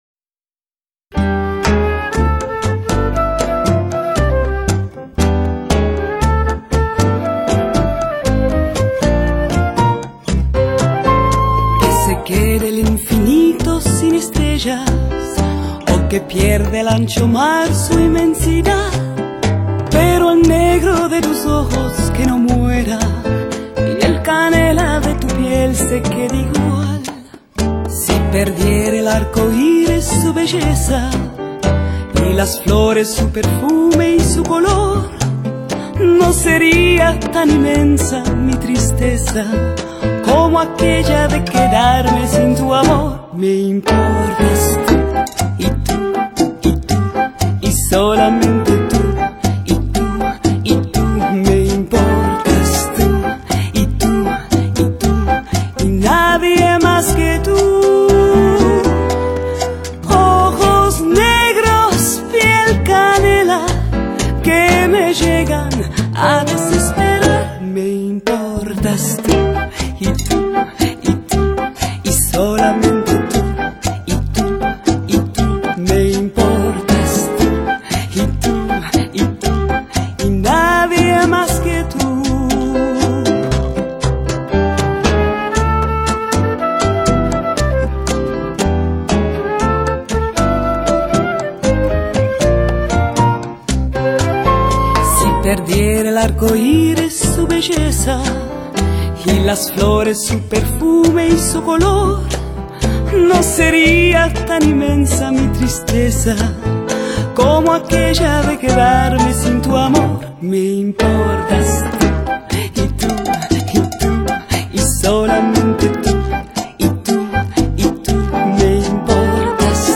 这张专辑17首歌曲除3首是附送的英文版本，其他14首全都是地道的拉丁语演唱，字正腔圆，韵味十足。